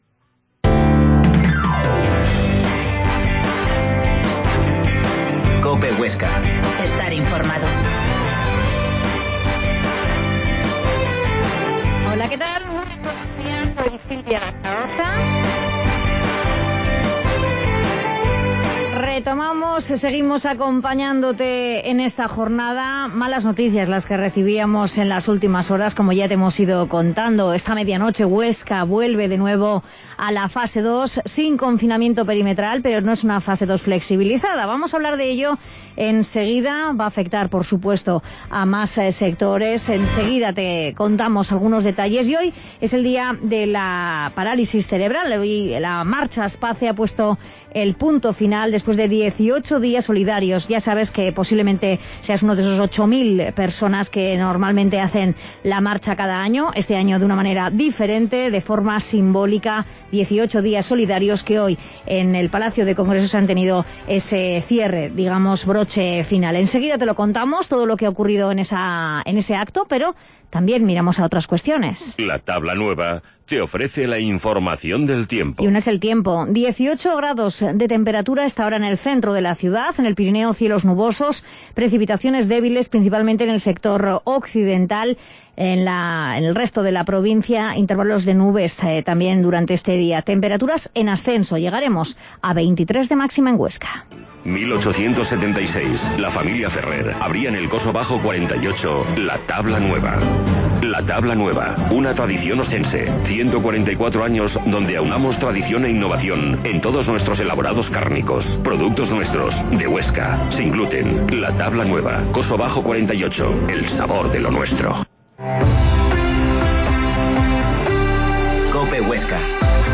Herrera en Cope Huesca 12,50h. Reportaje 8º Marcha Aspace